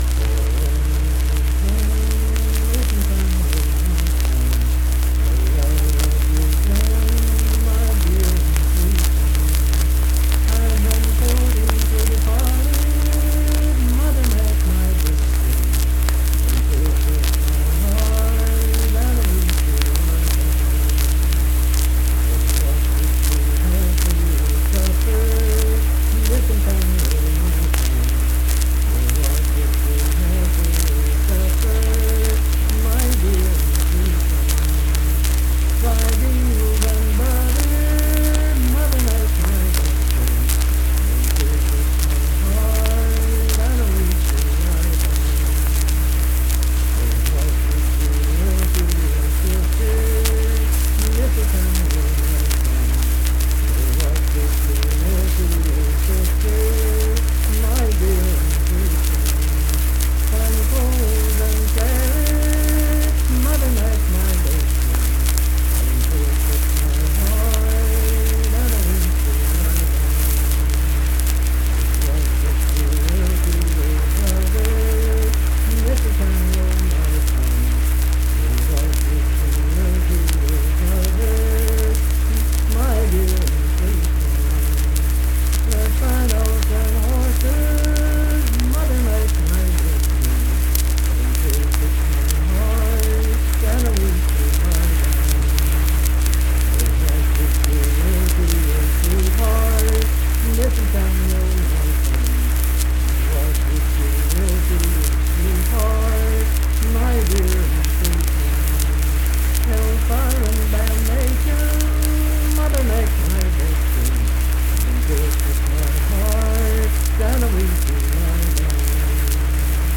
Unaccompanied vocal music
Verse-refrain 5d(4w/R).
Voice (sung)